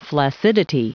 Prononciation du mot flaccidity en anglais (fichier audio)
Vous êtes ici : Cours d'anglais > Outils | Audio/Vidéo > Lire un mot à haute voix > Lire le mot flaccidity